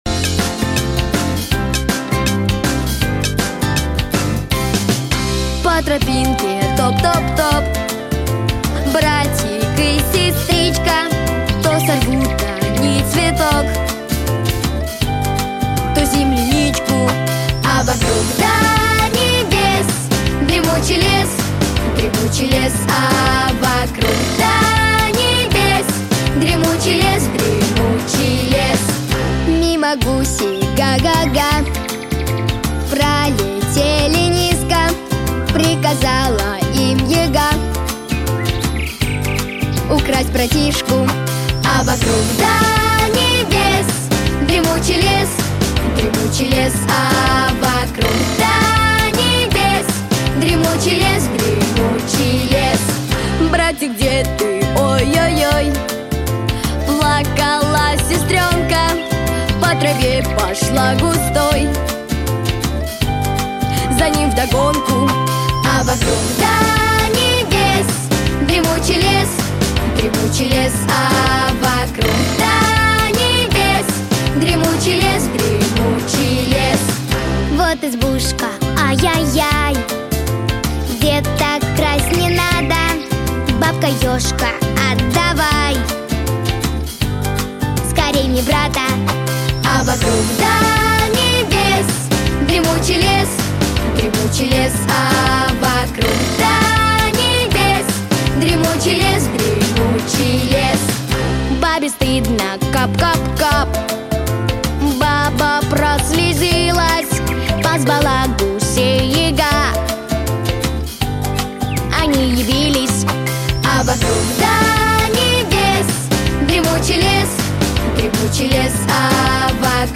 Песни из мультфильмов